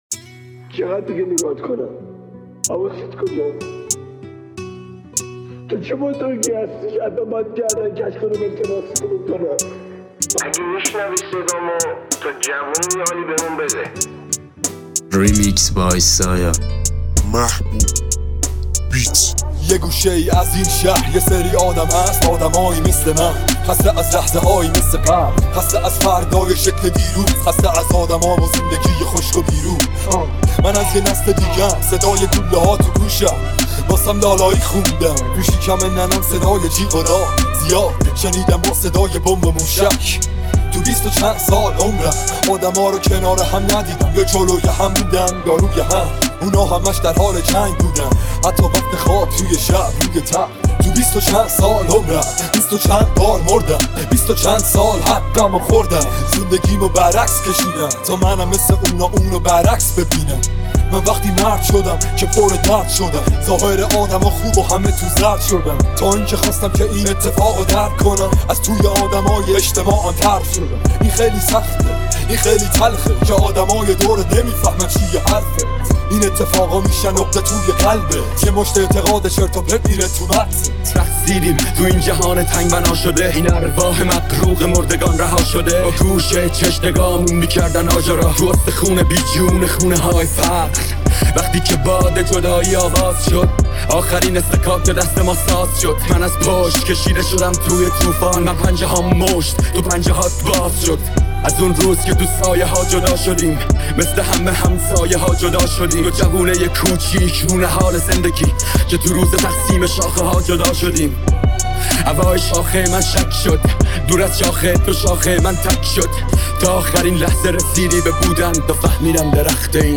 ریمیکس رپ